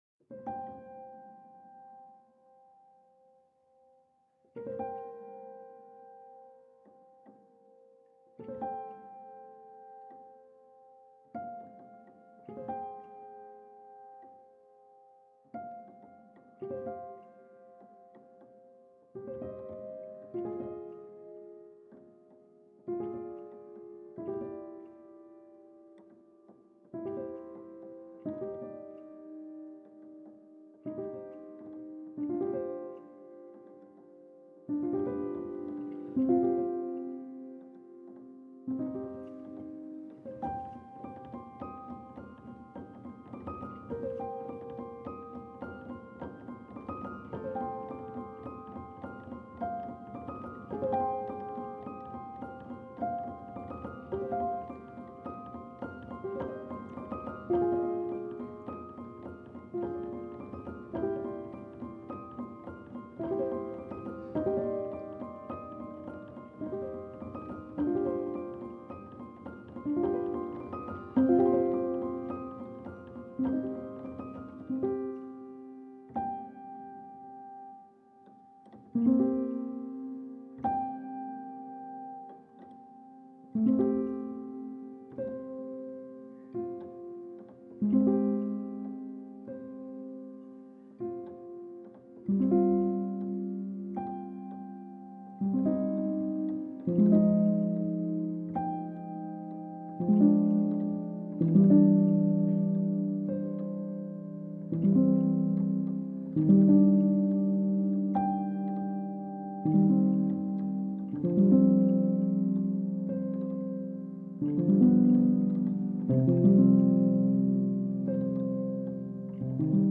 She sought the sound of nostalgia in the instrument.